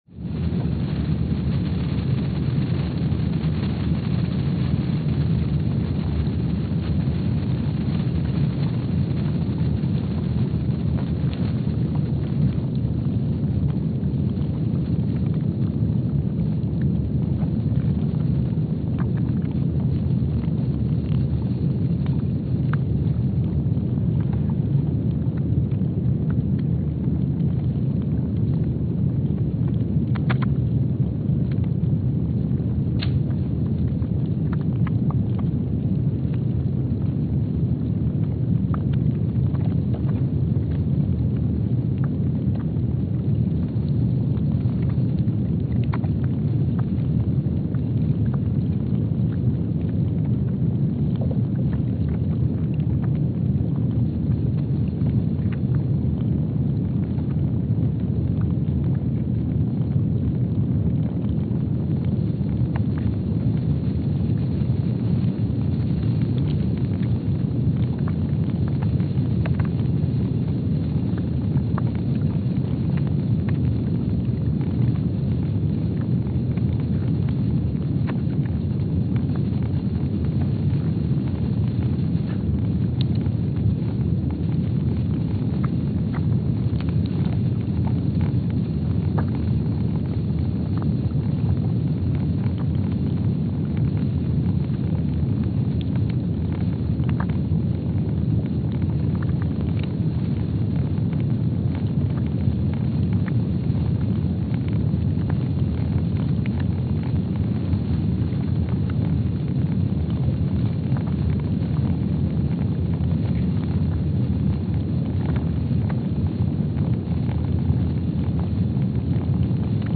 Palmer Station, Antarctica (seismic) archived on January 3, 2025
Station : PMSA (network: IRIS/USGS) at Palmer Station, Antarctica
Speedup : ×500 (transposed up about 9 octaves)
Loop duration (audio) : 05:45 (stereo)
Gain correction : 25dB
SoX post-processing : highpass -2 90 highpass -2 90